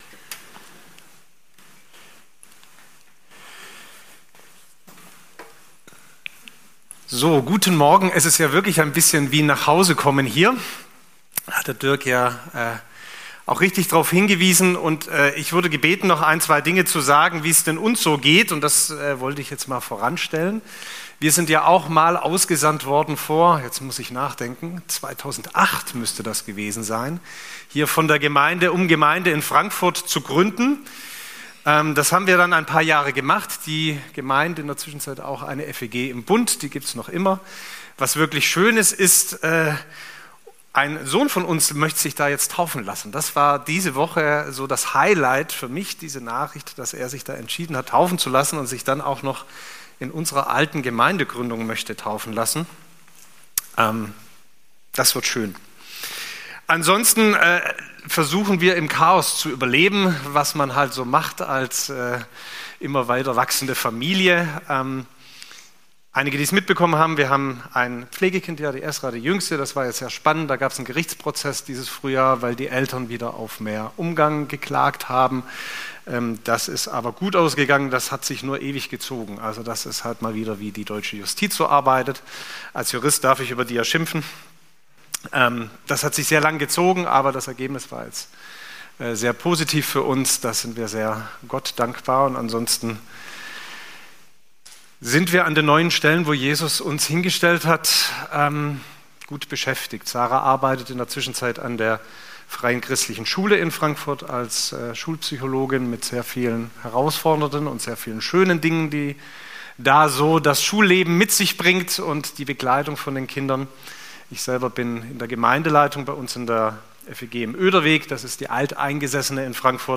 Predigten - FeG Steinbach Podcast